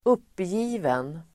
Ladda ner uttalet
uppgiven adjektiv, dejected Uttal: [²'up:ji:ven] Böjningar: uppgivet, uppgivna Synonymer: bedrövad, resignerad Definition: som har gett upp, resignerad (resigned) Exempel: ett uppgivet leende (a dejected smile)
uppgiven.mp3